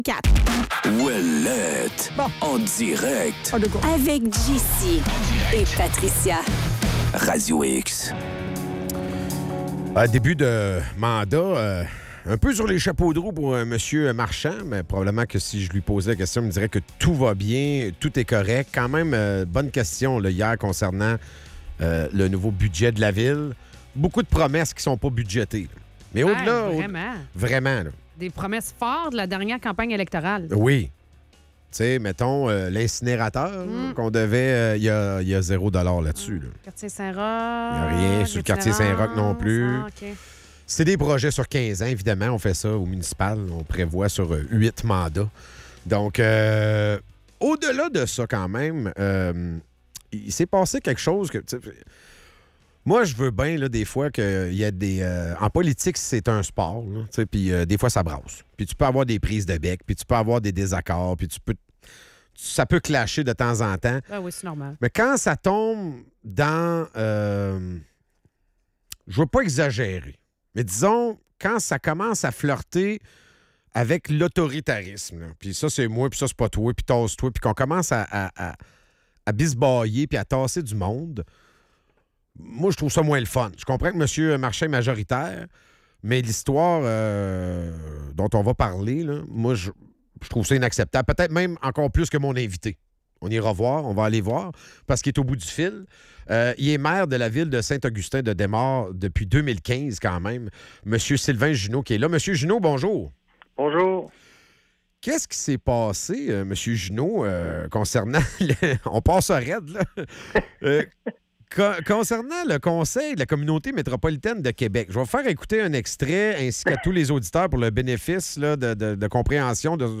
Entrevue avec Sylvain Juneau, maire de Saint-Augustin